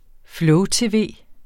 Udtale [ ˈflɔw- ]